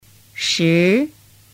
Shí Sứ 10